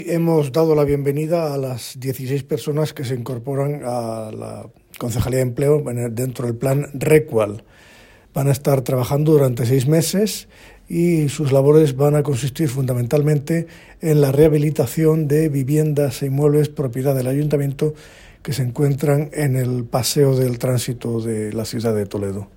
En un acto celebrado en el centro de formación Carlos III